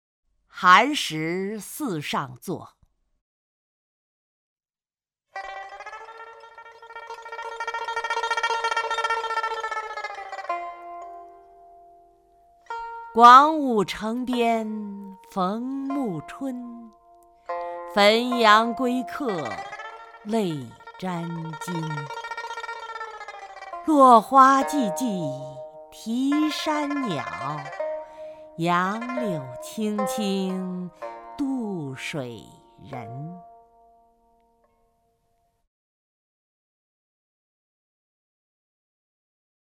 曹雷朗诵：《寒食汜上作》(（唐）王维) （唐）王维 名家朗诵欣赏曹雷 语文PLUS